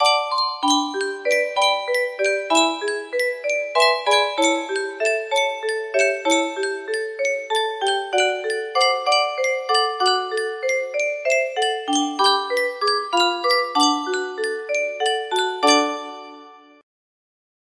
Yunsheng Music Box - In My Merry Oldsmobile 2301 music box melody
Full range 60